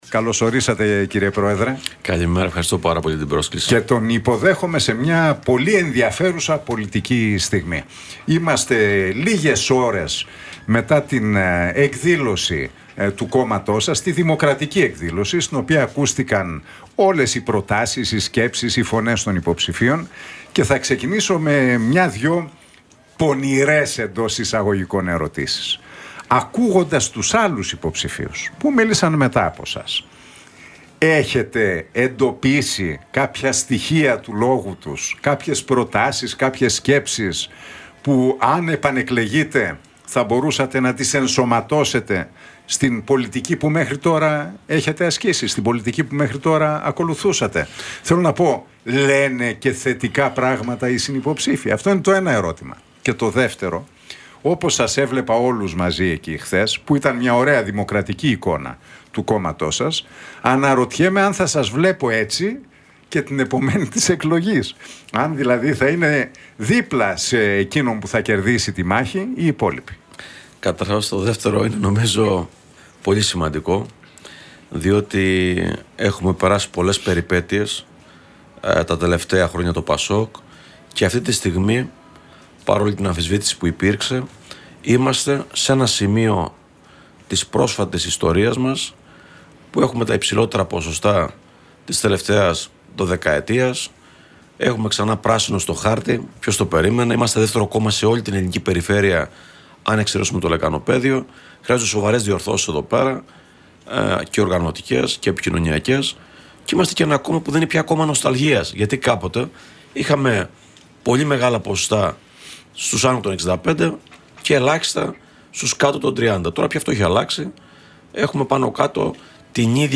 Ο Νίκος Ανδρουλάκης βρέθηκε στο στούντιο του Realfm 97,8 και παραχώρησε μια εφ'όλης της ύλης συνέντευξη στον Νίκο Χατζηνικολάου.